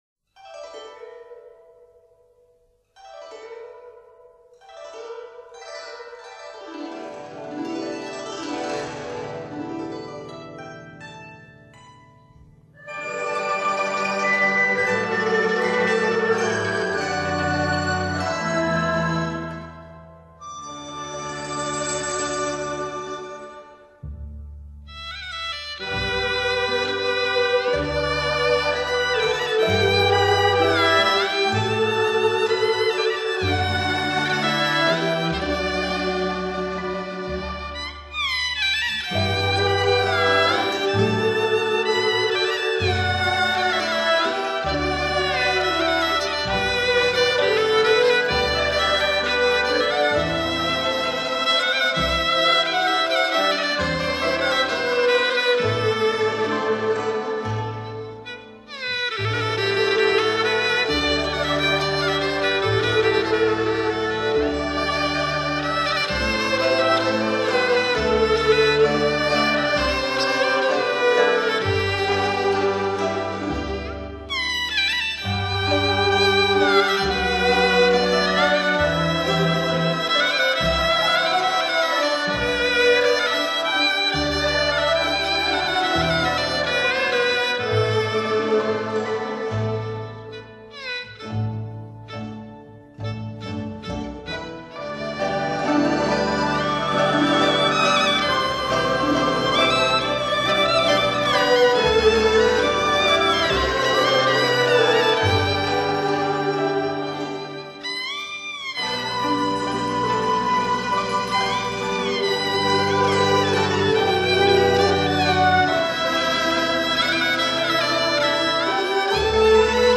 广东音乐的题材广泛多样，乐曲旋律清新优美，情绪活泼开朗，在中国民族器乐合奏曲中个性鲜明、独树一帜。